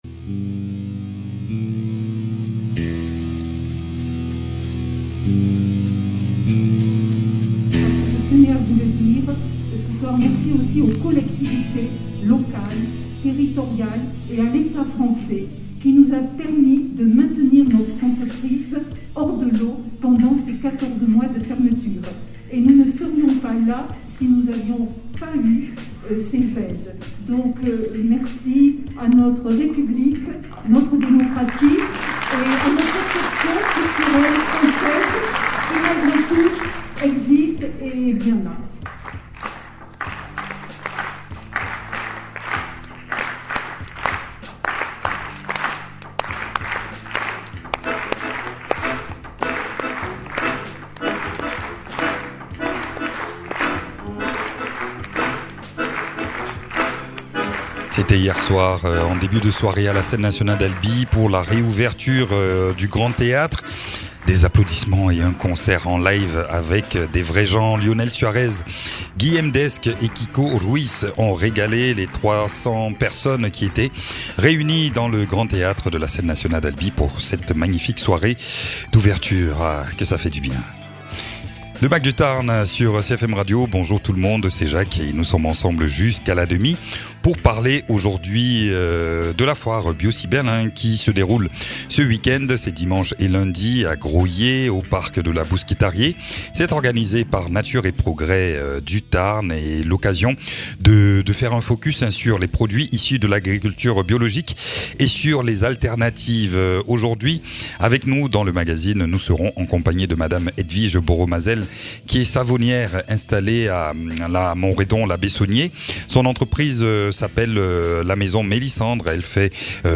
Adapter et préparer une manifestation d’envergure qui accueille habituellement des milliers de visiteurs en contexte covid n’est pas aisé. Nous en parlons avec notre invitée, savonnière artisanale qui a rejoint depuis peu le réseau Nature & Progrès du Tarn.